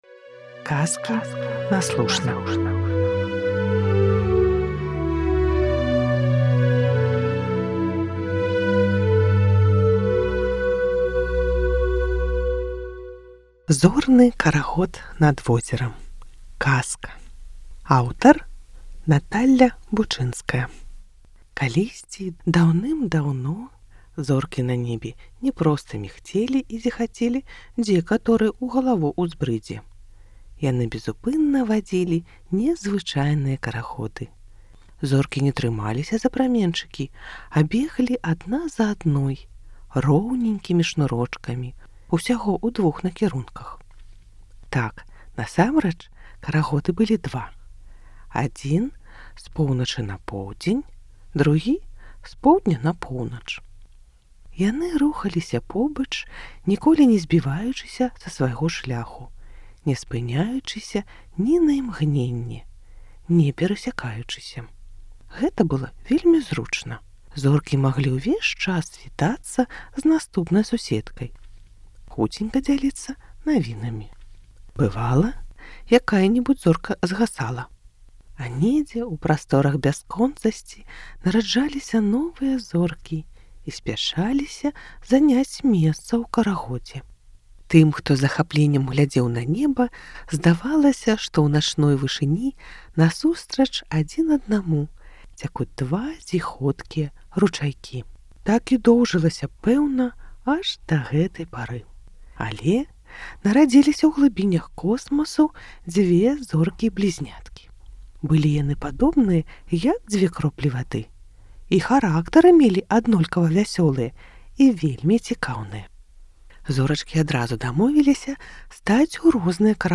Зорны карагод над возерам (казка) + аўдыё